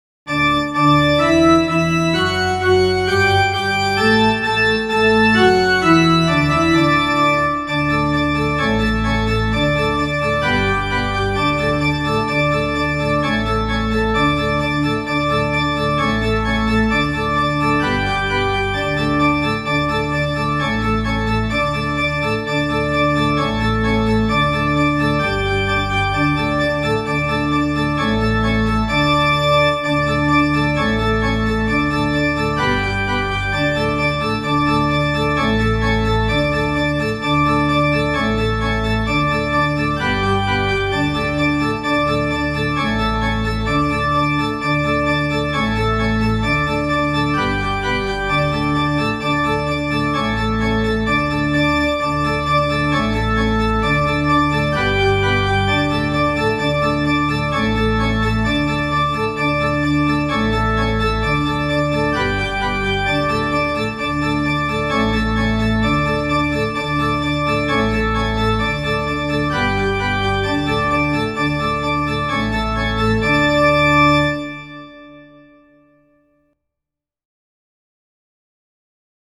Genre: Blues.
zingenineenkoor_zonderzang.mp3